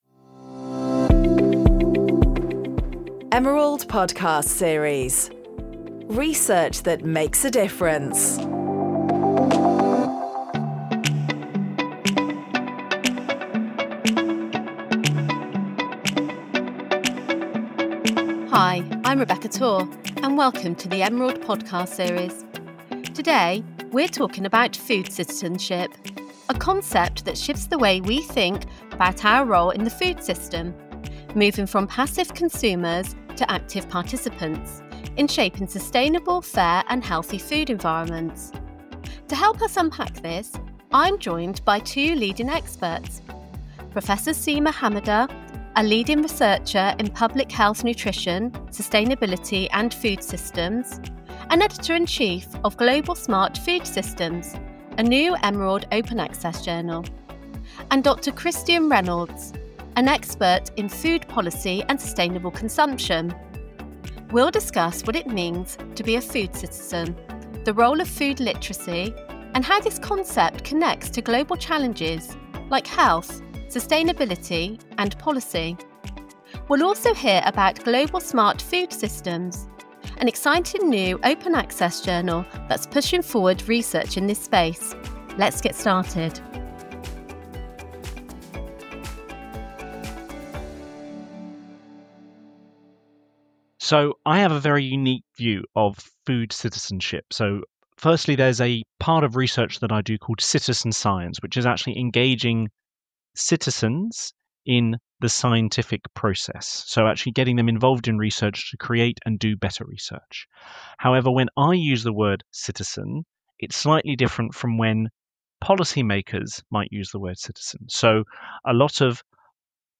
Join our hosts as they talk to experts using their research to create real impact in society. In each episode we explore the role research plays in our modern world, and ask how it can contribute to solving the comple…